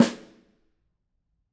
Snare2-HitSN_v7_rr1_Sum.wav